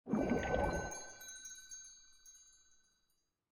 Minecraft Version Minecraft Version snapshot Latest Release | Latest Snapshot snapshot / assets / minecraft / sounds / mob / glow_squid / ambient5.ogg Compare With Compare With Latest Release | Latest Snapshot